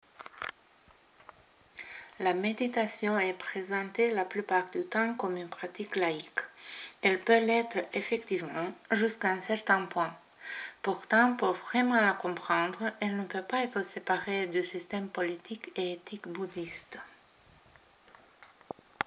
La méditation, au-delà des modes et des dérives – entretien